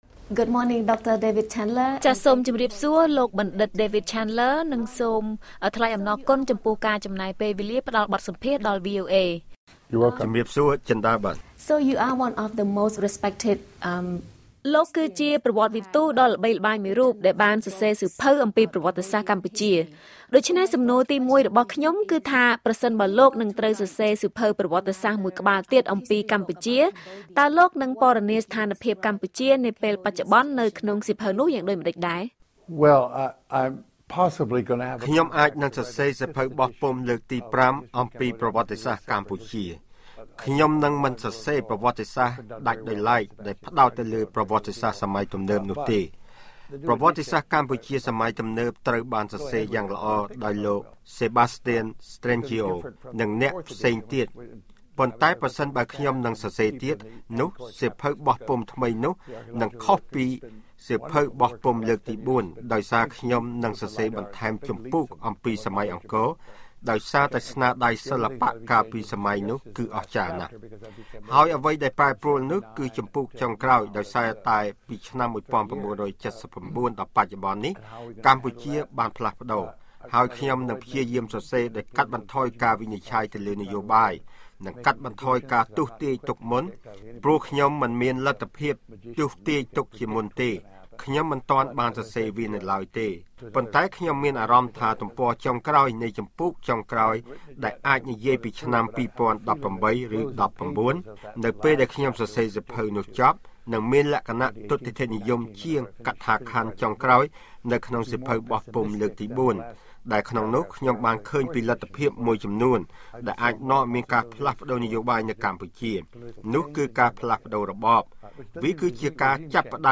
បទសម្ភាសន៍ VOA ៖ បញ្ហាដែលកម្ពុជាកំពុងជួបប្រទះនិងអនាគតរបស់កម្ពុជា